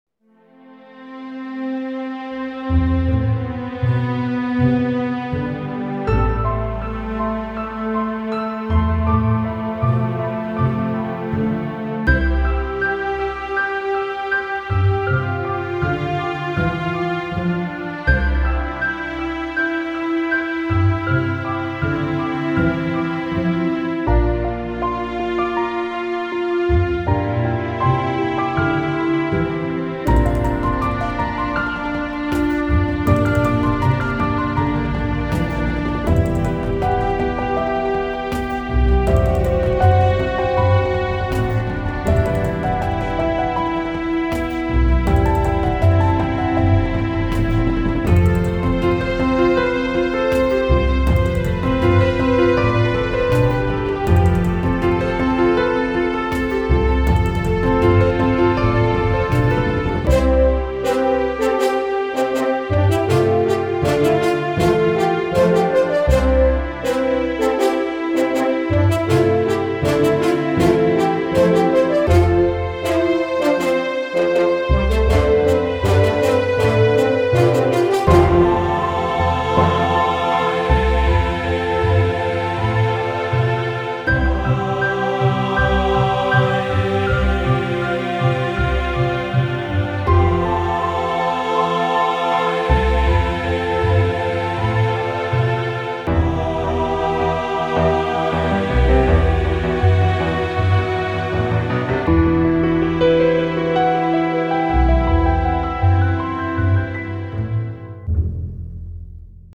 ΟΡΧΗΣΤΡΙΚΑ